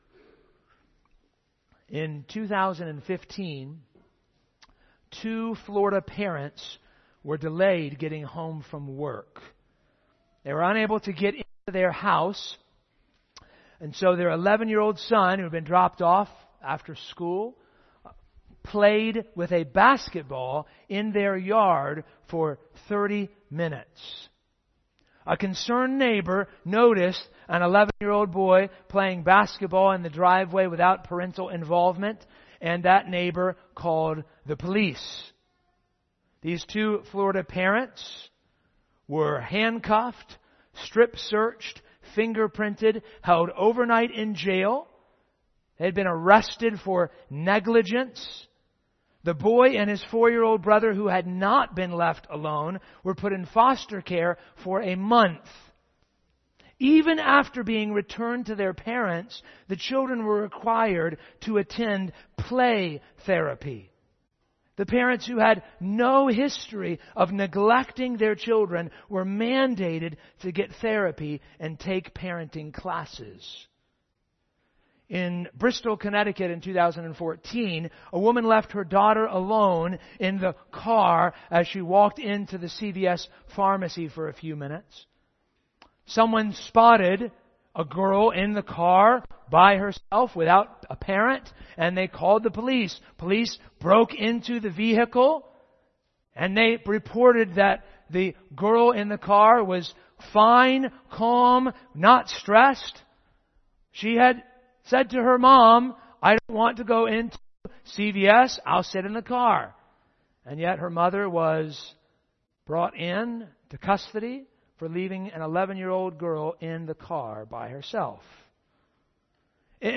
This Week's Sermon